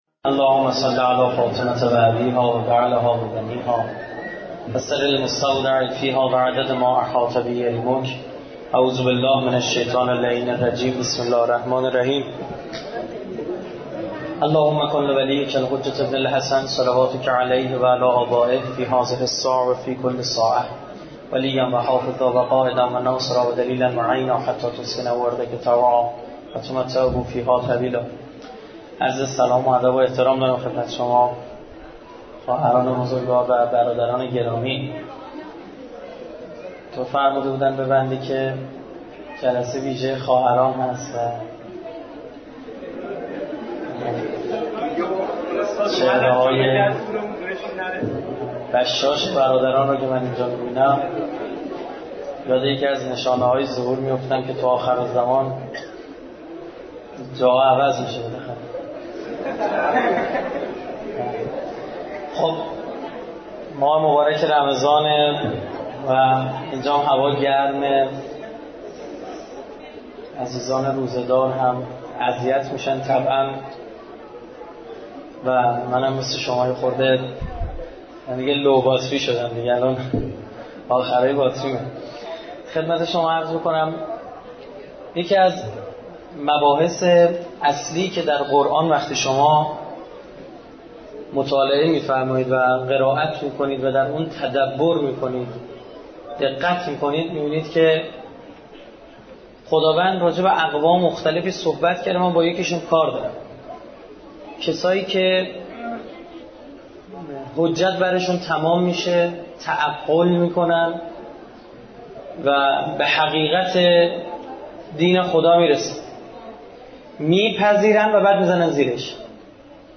زمان: 51:00 | حجم: 11.9 MB | تاریخ: 9 / 4 / 1394 | مکان: شهر قدس